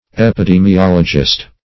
\ep`i*de`mi*ol"o*gist\
epidemiologist.mp3